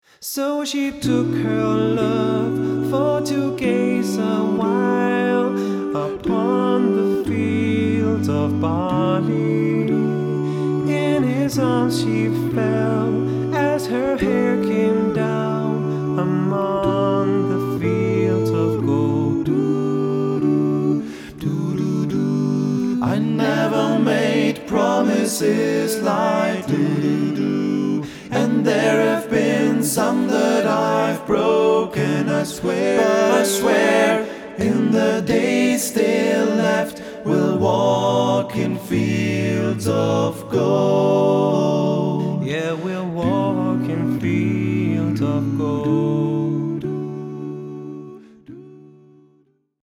Quartett